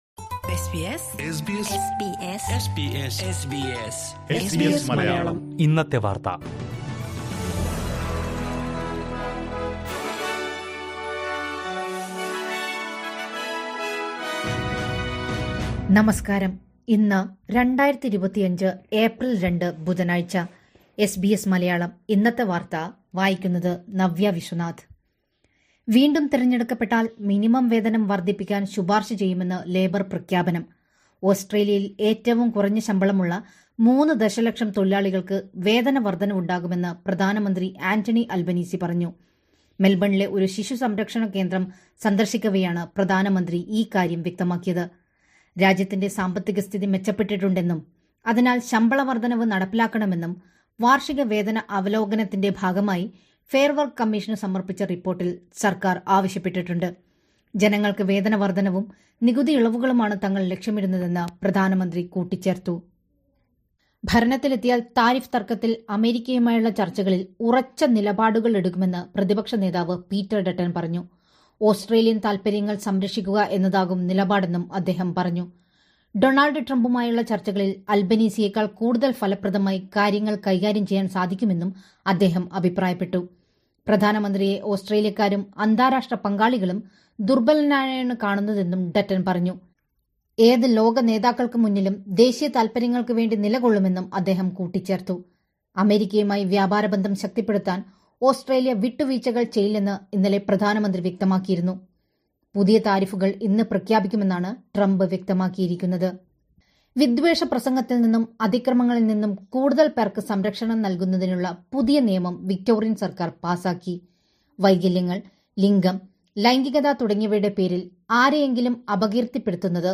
2025 ഏപ്രിൽ രണ്ടിലെ ഓസ്‌ട്രേലിയയിലെ ഏറ്റവും പ്രധാന വാര്‍ത്തകള്‍ കേള്‍ക്കാം...